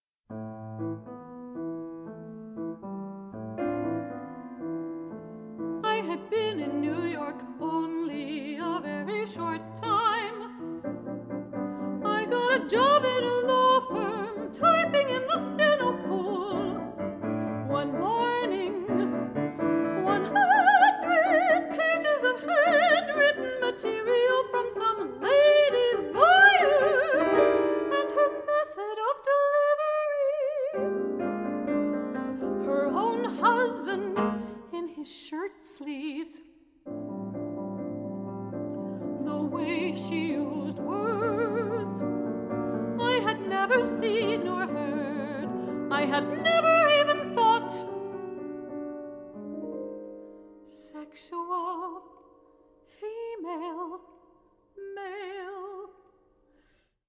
24 bit digital recording